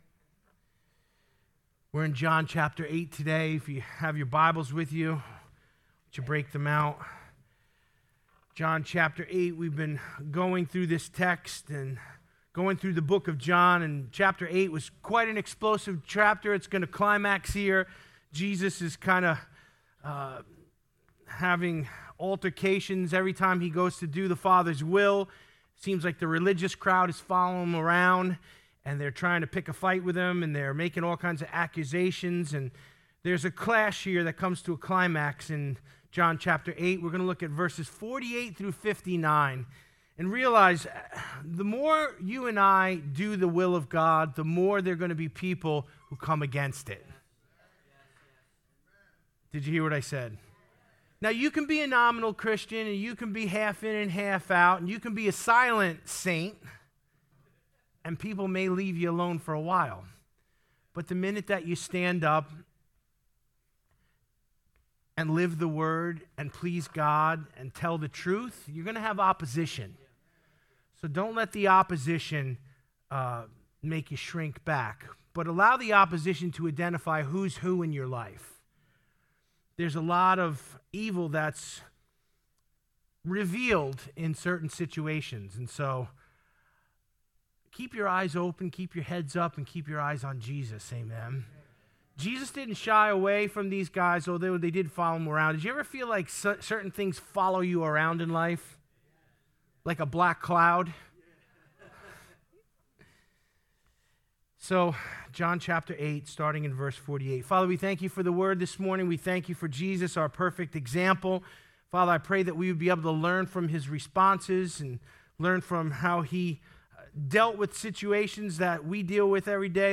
Full Gospel Center Sermons